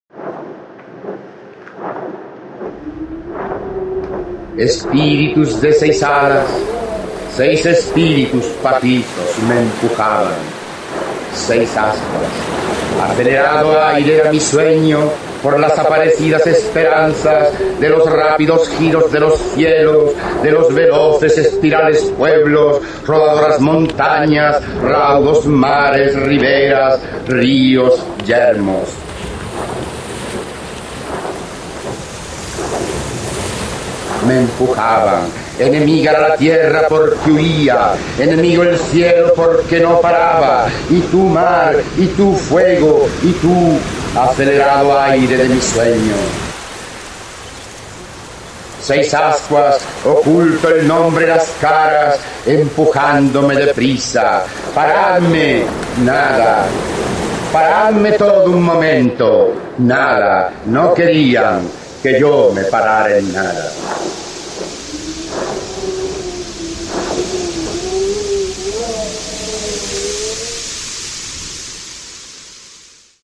Archivo de sonido con la voz del escritor español Rafael Alberti, quien recita su poema “Los ángeles de la prisa" (Sobre los ángeles, 1927-1928).